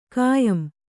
♪ kāyam